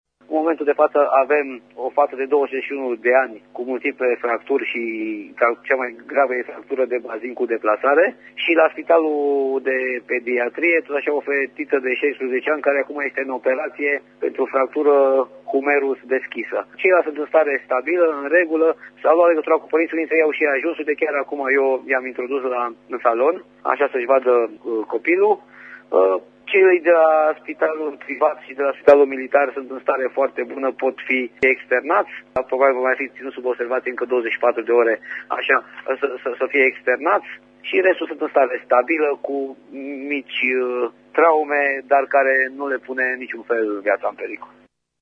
Alte 26 de persoane au fost rănite, iar una este în stare gravă, a declarat pentru RTM prefectul județului Brașov, Ciprian Băncilă: